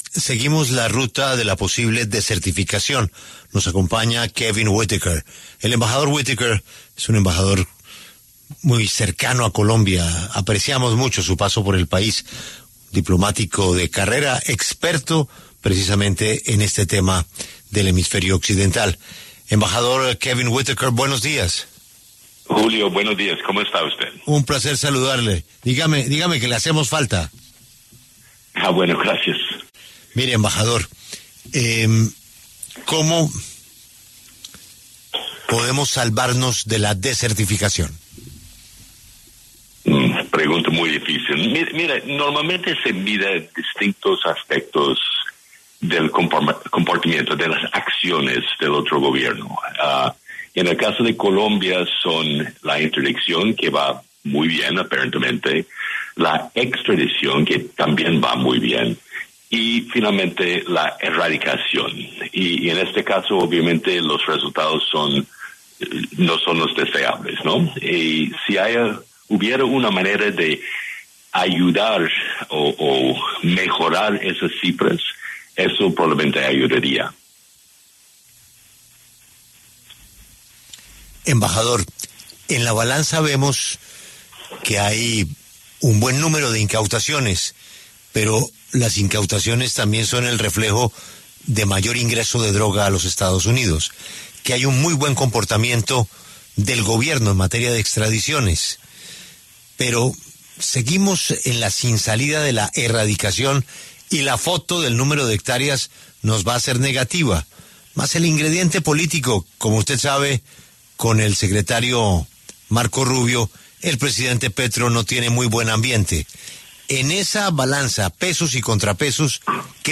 En diálogo con La W, Kevin Whitaker, exembajador de Estados Unidos en Colombia, se refirió a la posibilidad de que Colombia sea descertificado como socio en la lucha antidrogas por Estados Unidos.